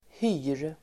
Uttal: [hy:r]